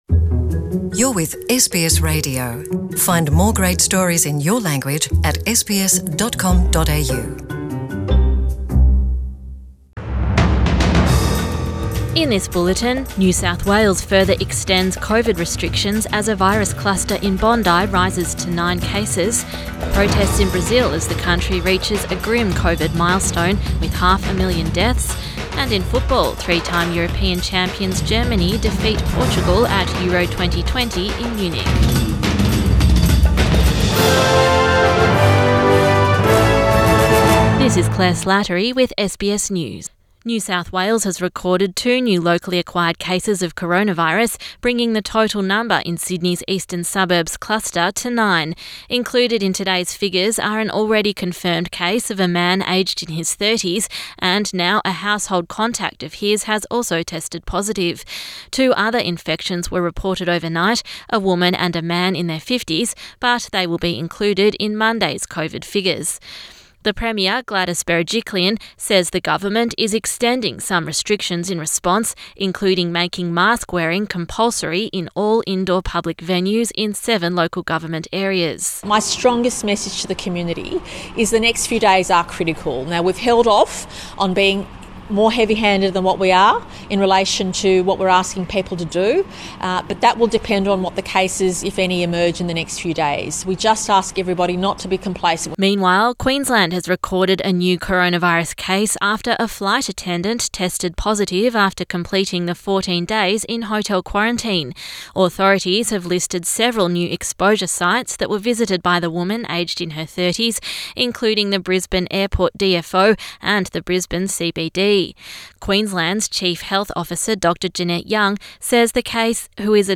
PM bulletin 20 June 2021